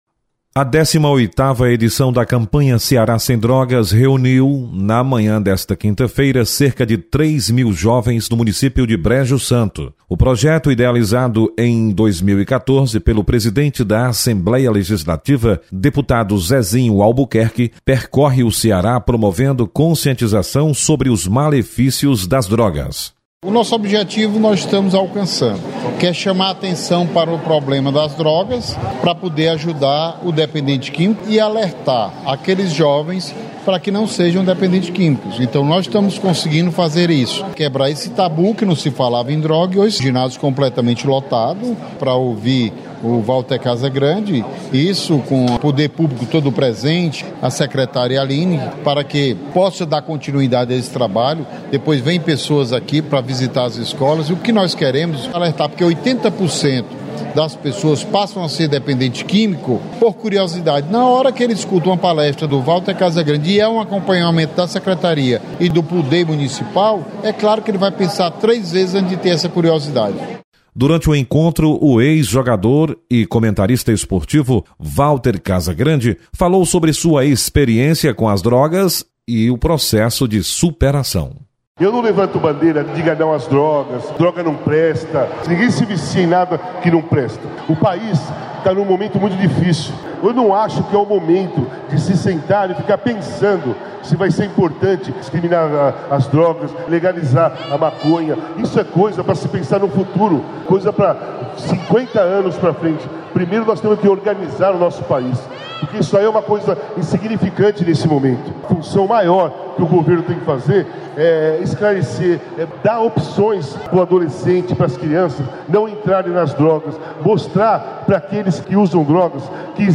Campanha Ceará sem Drogas chega ao município de Brejo Santo. Repórter